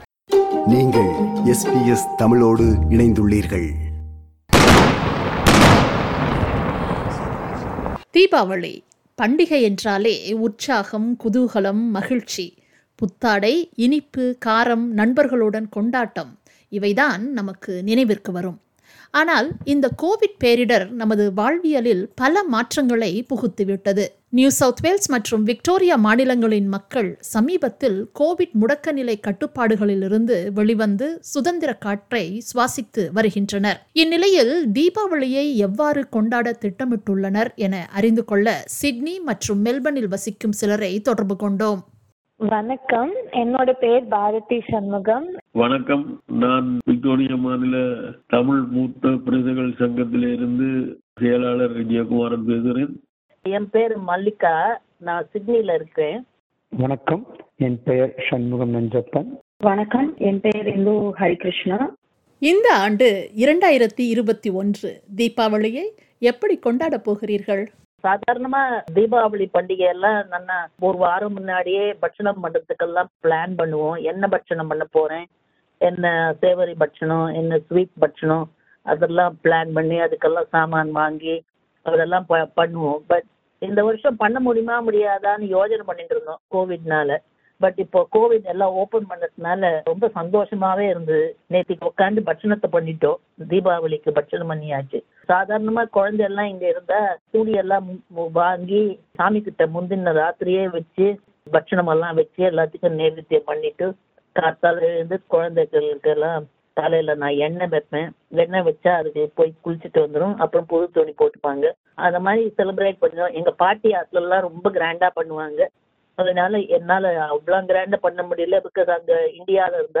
Deepavali 2021 - Sydney and Melbourne people after coming out from lockdown are going to celebrate Deepavali this year. Some of our listeners from Sydney and Melbourne shares their views and comments about their preparation for Deepavali celebration